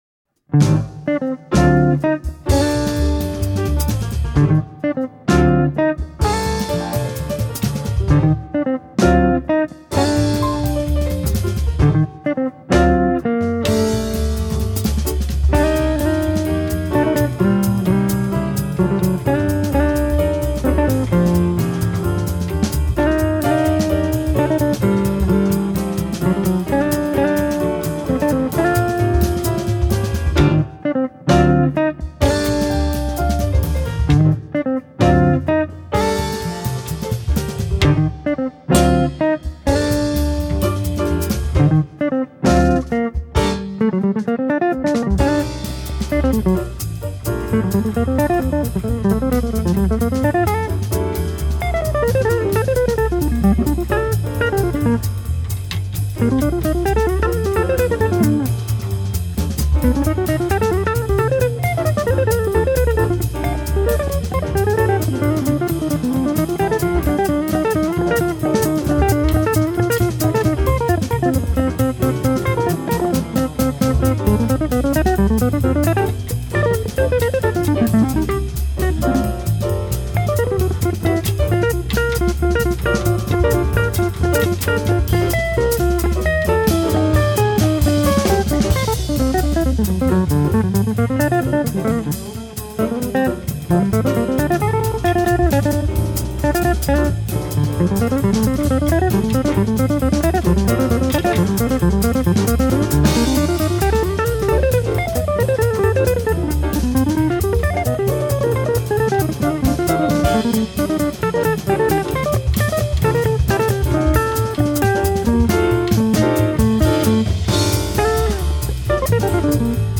He plays jazz guitar and the song is an original he wrote called “Sixth Street”.
It’s a straight-ahead jazz tune
piano
bass